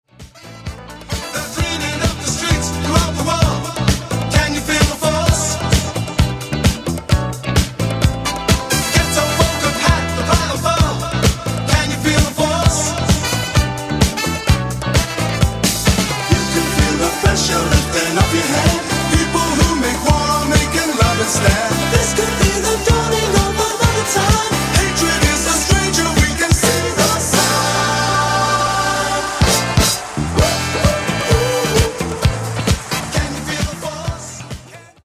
Genere:   Disco Funk